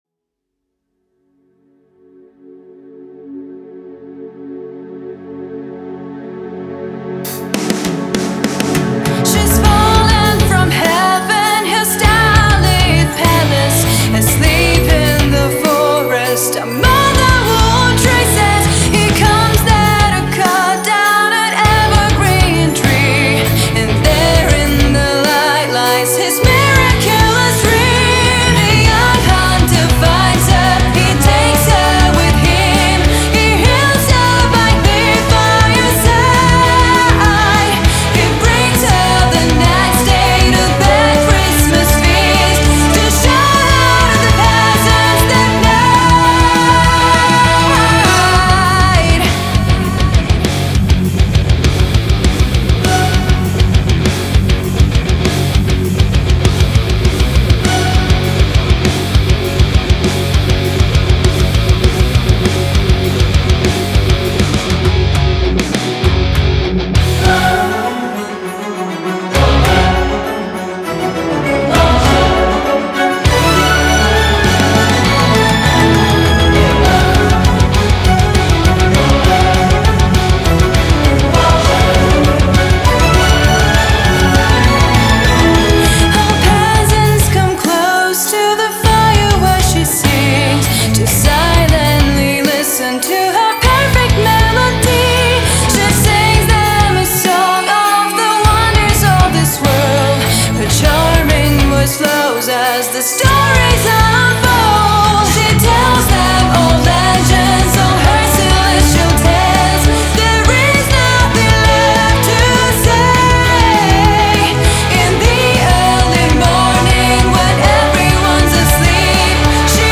Дебютный сингл молодого коллектива из Москвы. https